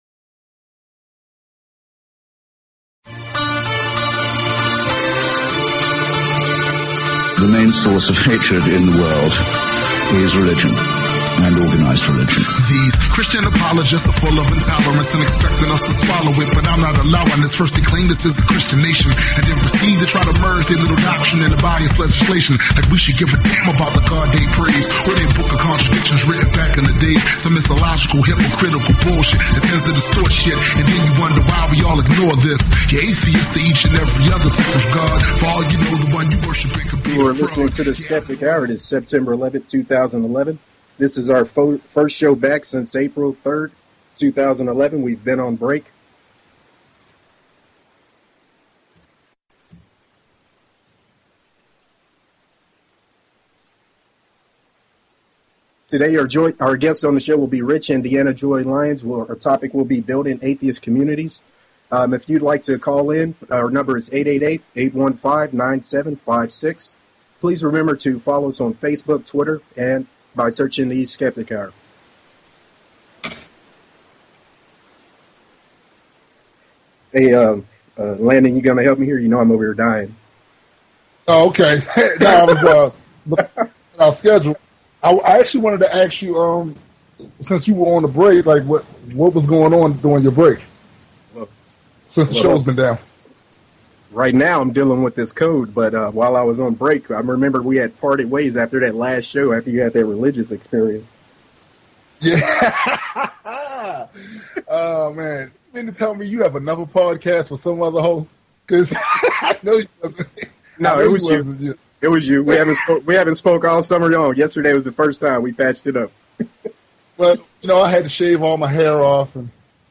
Talk Show Episode, Audio Podcast, The_Skeptic_Hour and Courtesy of BBS Radio on , show guests , about , categorized as